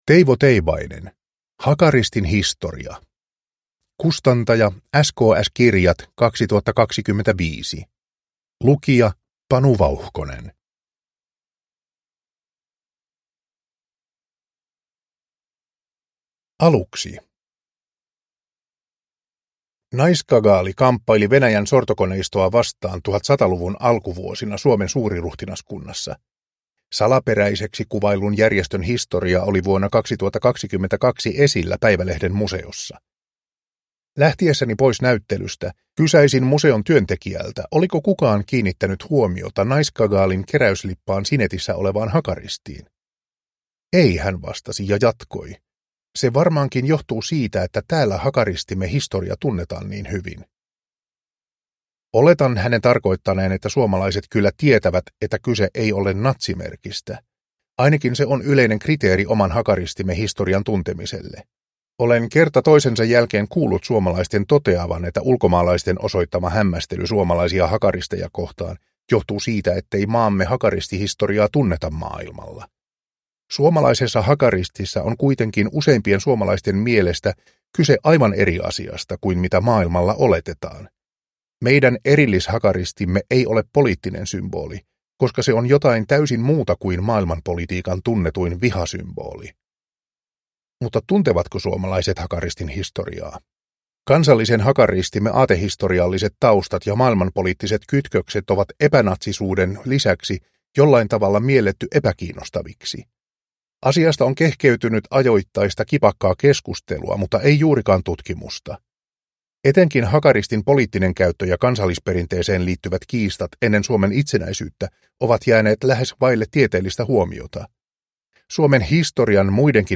Hakaristin historia – Ljudbok